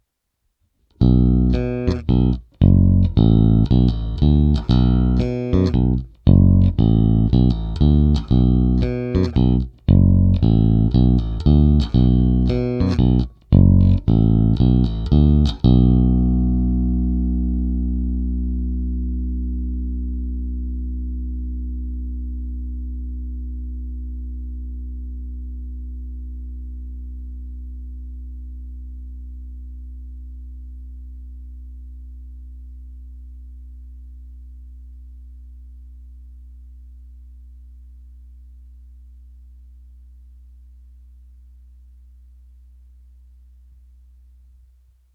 Není-li uvedeno jinak, ukázky jsou provedeny rovnou do zvukové karty a jen normalizovány. Hráno vždy nad snímačem.
Basy a výšky přidané na 50%